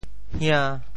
邢 部首拼音 部首 阝 总笔划 6 部外笔划 4 普通话 xíng 潮州发音 潮州 hian5 白 中文解释 邢 <名> 古国名 [Xing state]。
hia~5.mp3